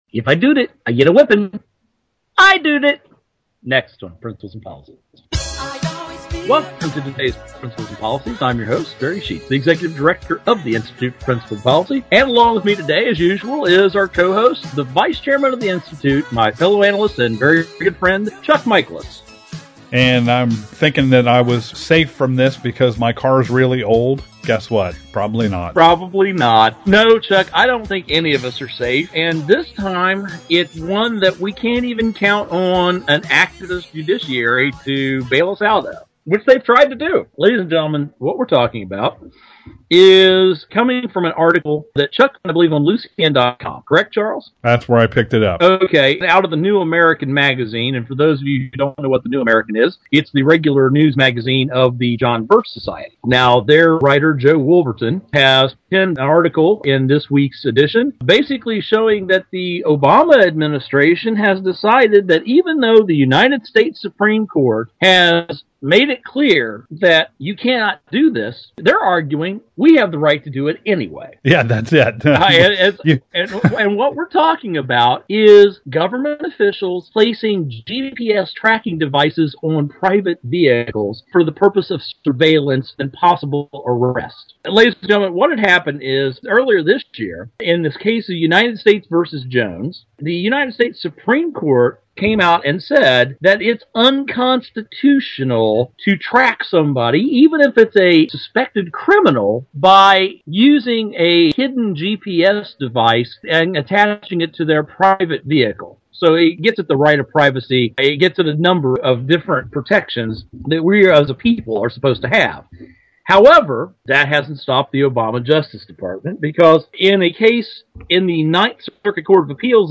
Our Principles and Policies radio show for Thursday June 7, 2012.